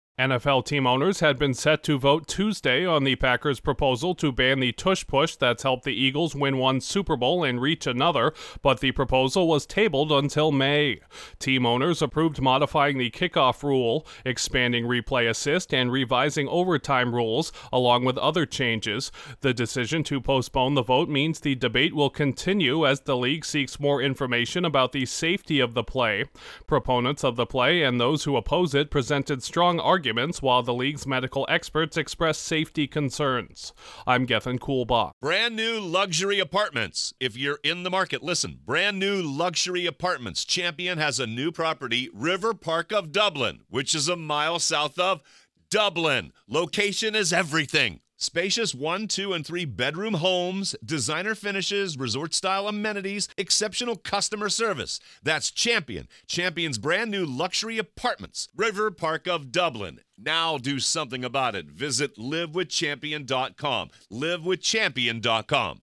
The infamous tush push play in the NFL will live to see another day. Correspondent